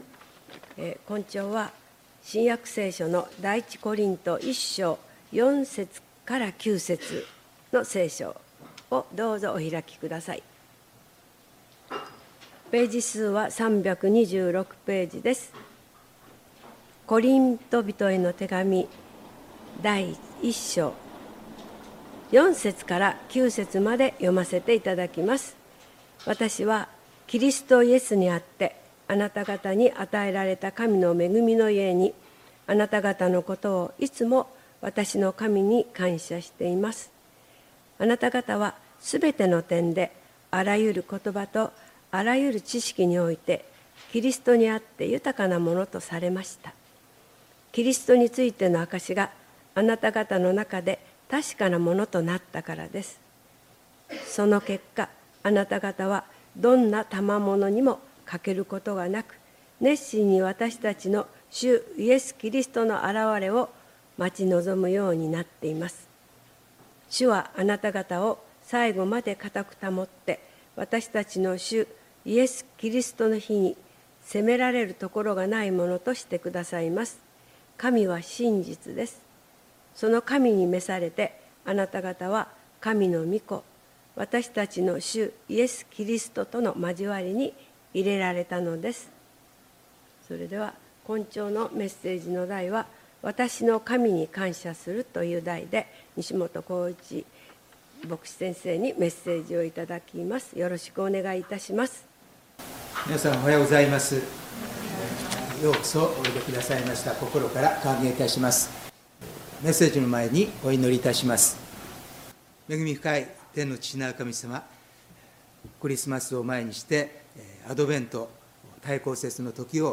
礼拝メッセージ「私の神に感謝する」│日本イエス・キリスト教団 柏 原 教 会